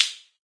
plasticplastic.ogg